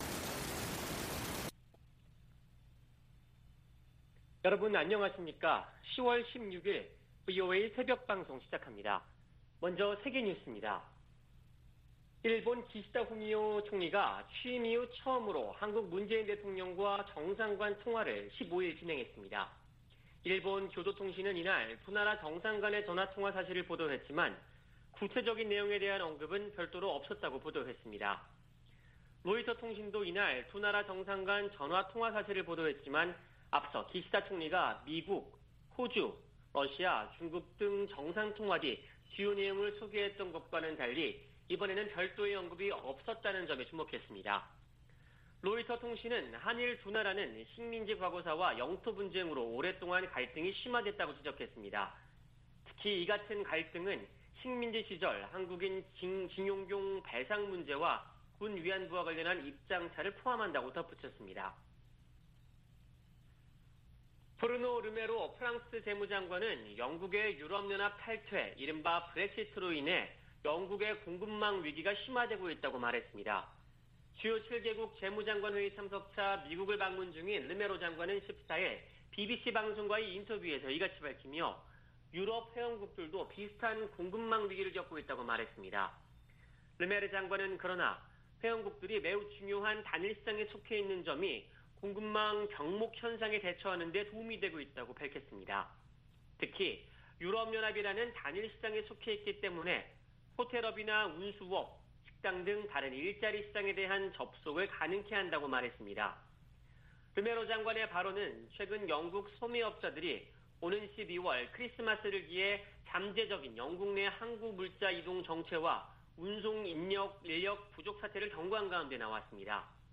VOA 한국어 '출발 뉴스 쇼', 2021년 10월 16일 방송입니다. 미국이 탈퇴 3년 만에 유엔 인권이사회 이사국으로 선출됐습니다. 미 국무부가 북한 핵 문제 해결을 위해 동맹과 활발한 외교를 펼치고 있다고 밝혔습니다. 미국과 한국 등 30여개국이 세계적으로 증가하는 랜섬웨어 공격 대응에 적극 공조하기로 했습니다.